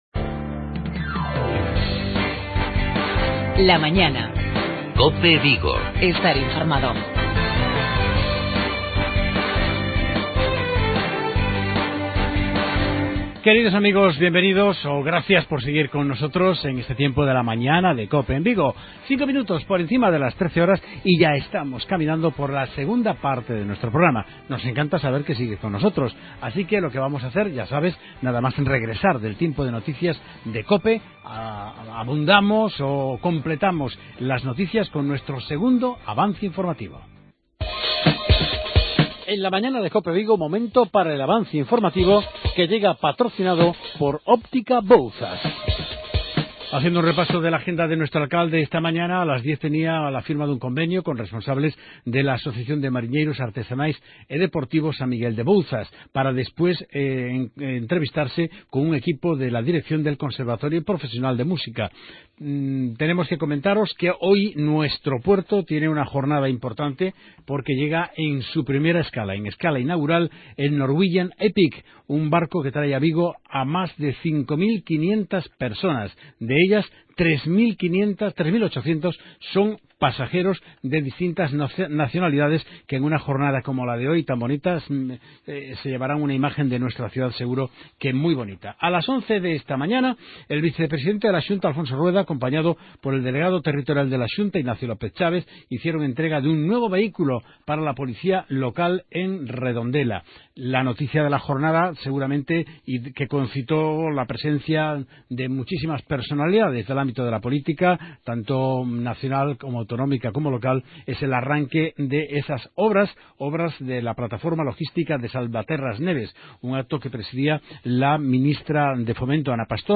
Avance informativoSALUD Y BIENESTAREntrevista a